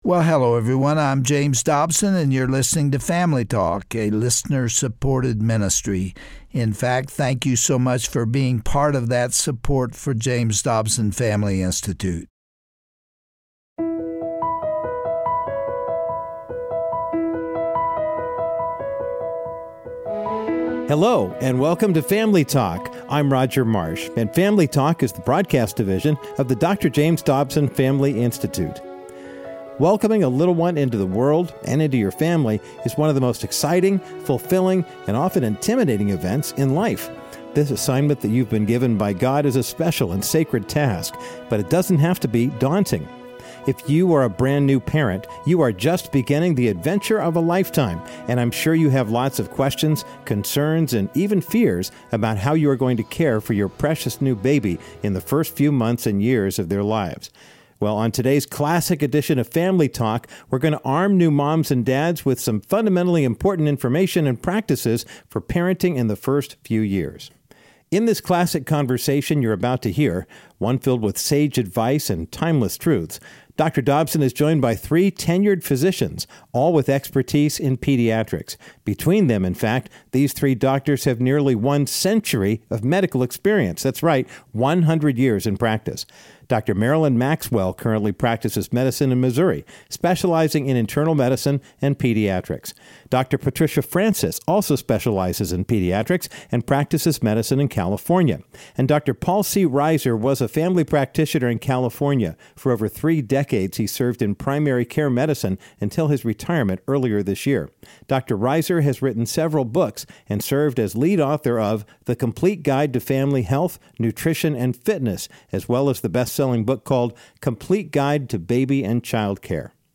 As Dr. James Dobson interviews a panel of pediatric doctors, you’ll learn about the importance of breastfeeding, best practices for treating croup, and some of the presumed causes of Sudden Infant Death Syndrome, also known as SIDS. Because babies have an insatiable curiosity in their first year, it’s essential to child-proof your home by placing medicines, cleaning products, and other potentially toxic items out of reach.